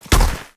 Sfx_creature_snowstalker_walk_02.ogg